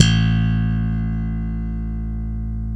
Index of /90_sSampleCDs/East Collexion - Bass S3000/Partition A/SLAP BASS-B